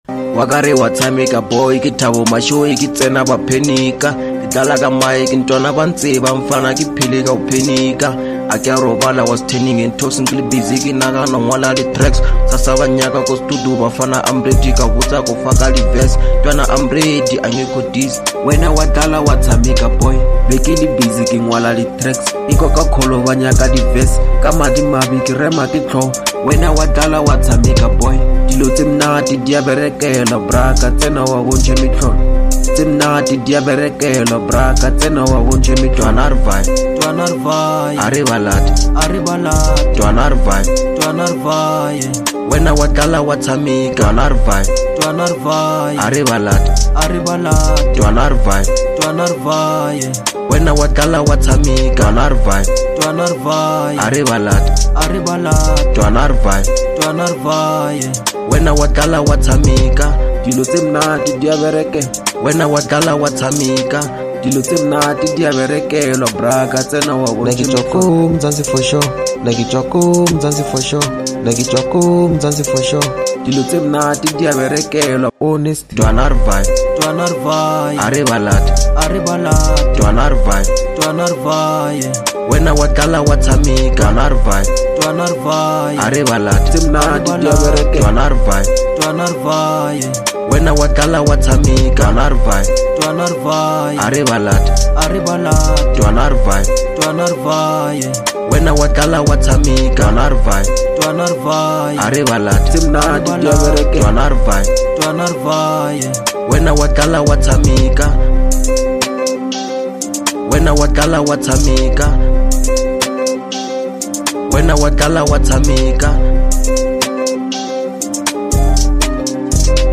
02:07 Genre : Hip Hop Size